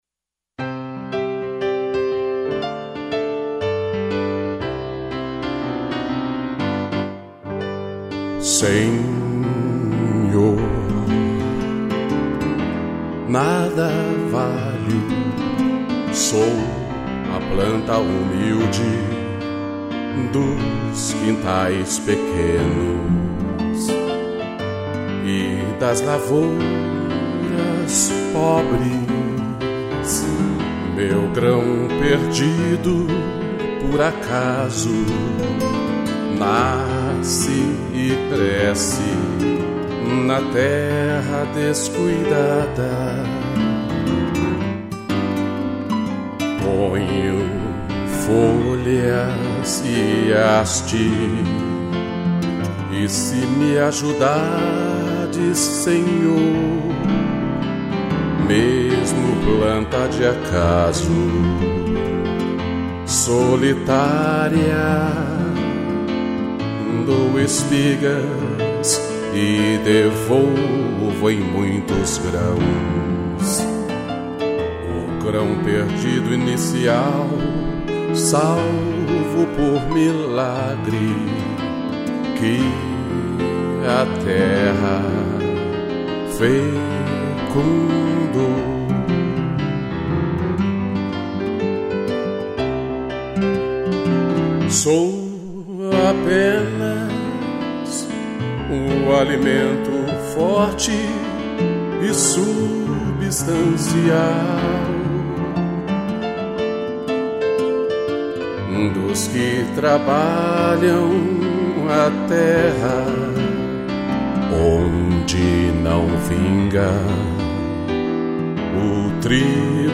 voz e violão
piano